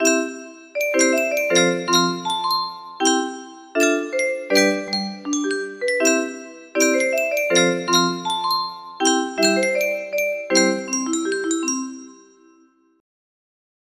스와니강(화음) music box melody
Grand Illusions 30 (F scale)